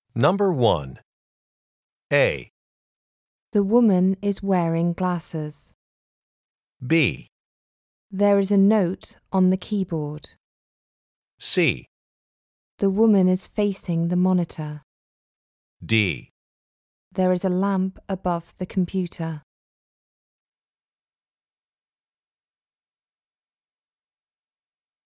For each question in this part, you will hear four statements about a picture in your test book.
The statements will not be printed in your test book and will be spoken only one time.